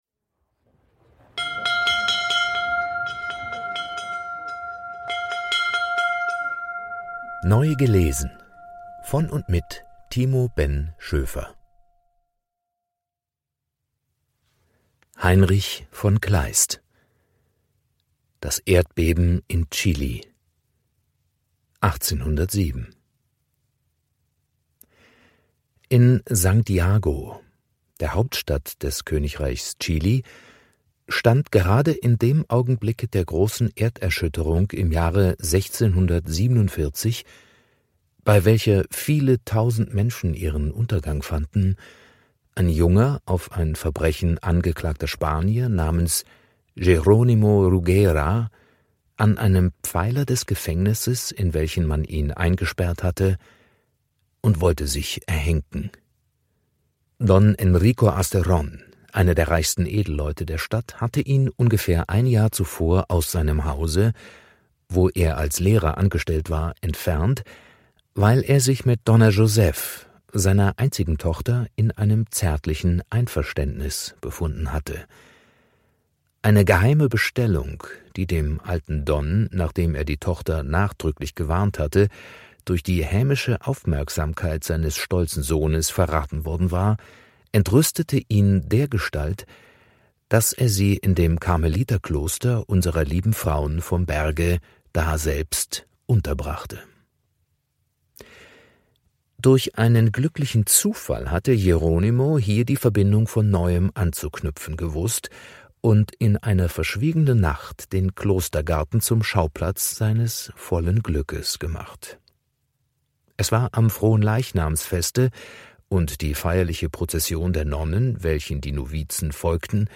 Literatur des 19.Jahrhunderts,vorgelesen